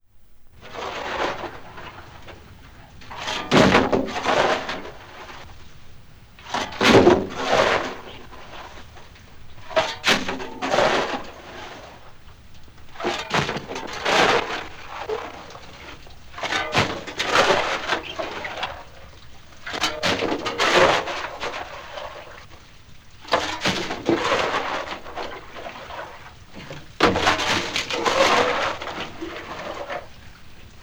Shovelling_in_a_coal_mine_ogp.wav